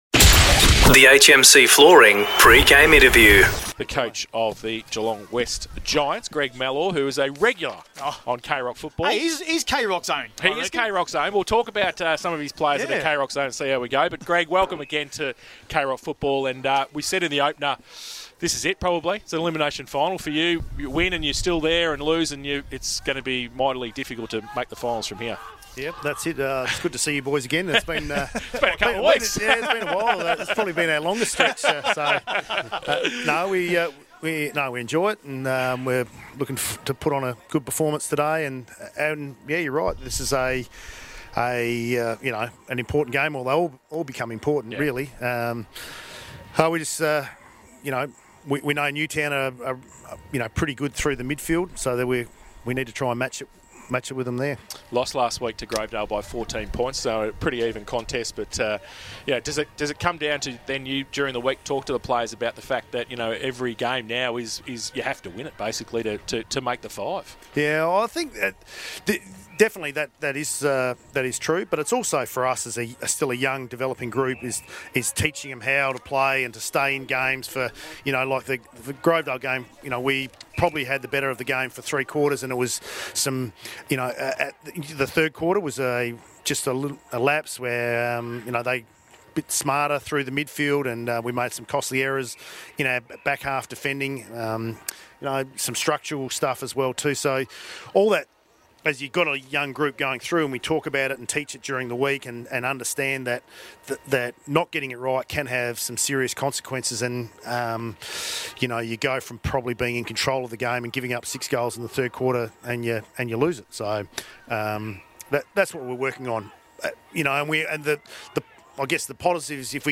2022 – GFL ROUND 14 – NEWTOWN & CHILWELL vs. GEELONG WEST: Pre-match Interview